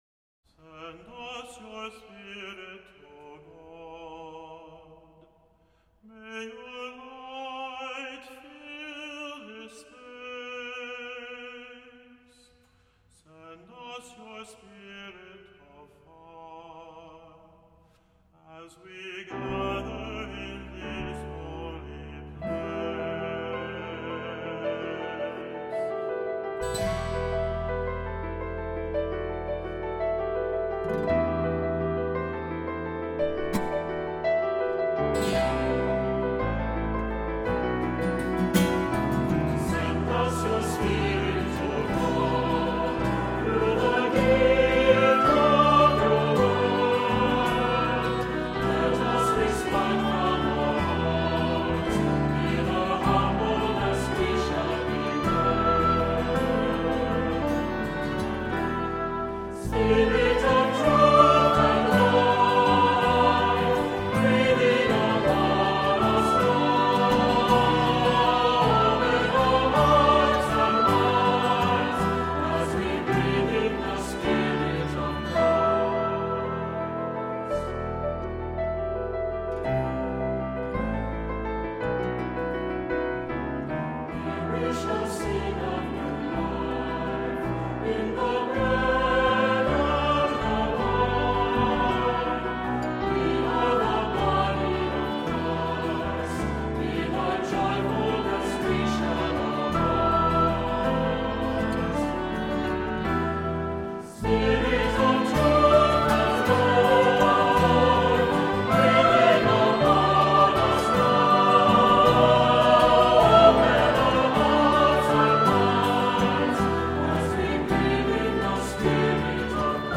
Accompaniment:      Keyboard, C Instrument
Music Category:      Christian